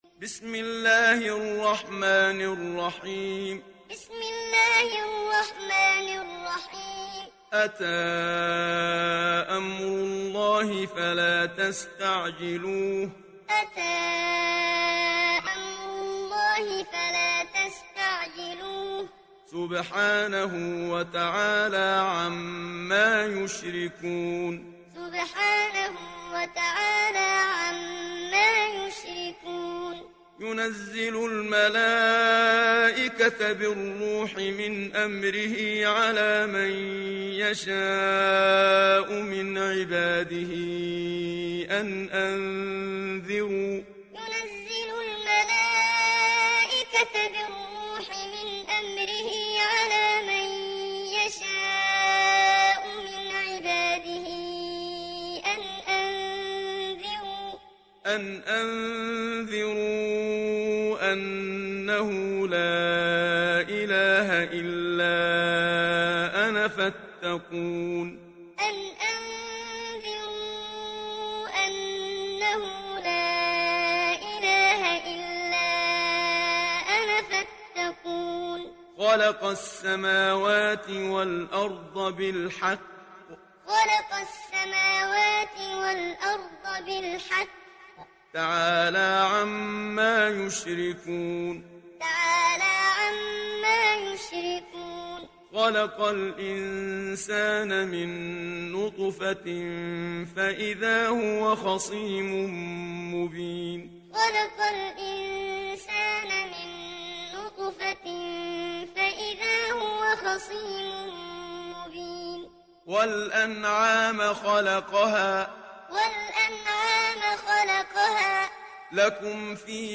دانلود سوره النحل محمد صديق المنشاوي معلم